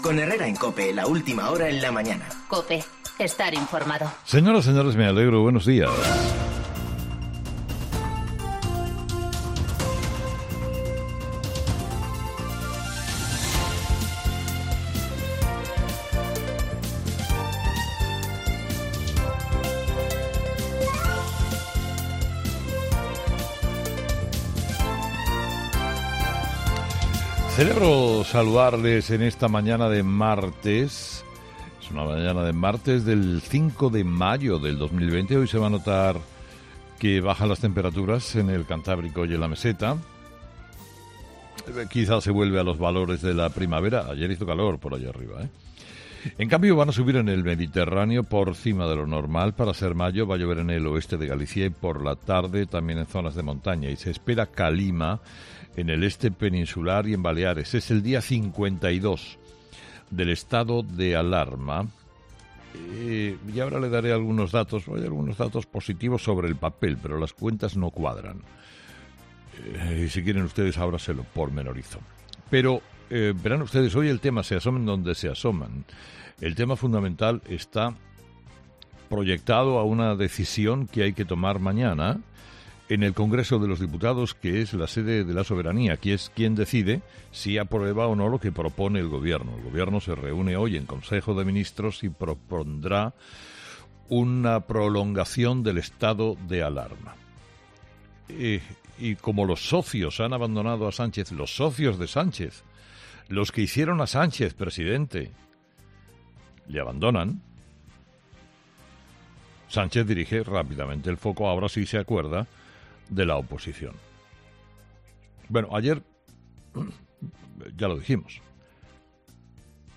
Carlos Herrera ha explicado los detalles que hay detrás de la nueva prórroga del estado de alarma que quiere conseguir Sánchez en el Congreso